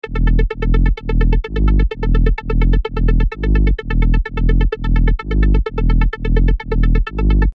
Rumble: Bass Collection
Earth-Shaking Basslines & Groovey Basslines for Dancefloor Ready Tunes.
BASS_-_Tech_House_1.mp3